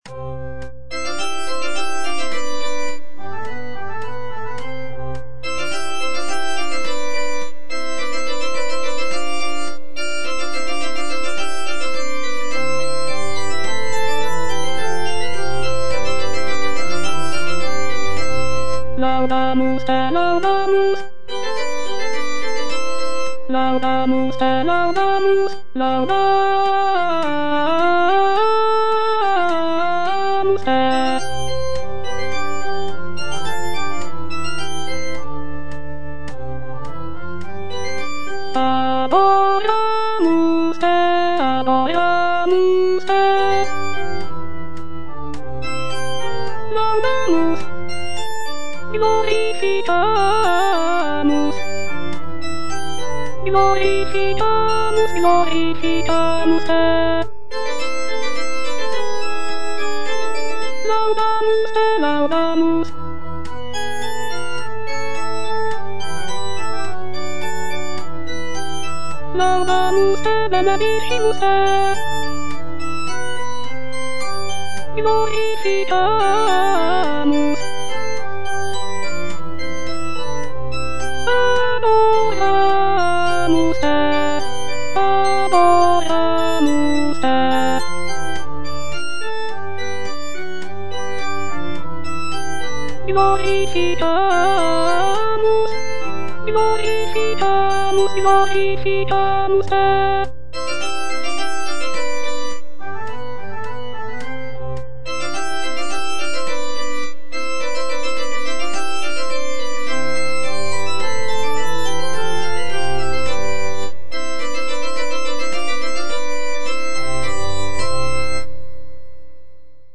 "Missa Sapientiae" is a choral work composed by Antonio Lotti, an Italian Baroque composer, in the late 17th century. This sacred composition is a Mass setting, which includes sections such as Kyrie, Gloria, Credo, Sanctus, and Agnus Dei. Lotti's "Missa Sapientiae" showcases his mastery of counterpoint, with intricate polyphonic textures and rich harmonies. It is characterized by its expressive melodies, dramatic contrasts, and a sense of solemnity.